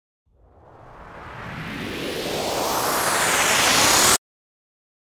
FX-1828-RISER
FX-1828-RISER.mp3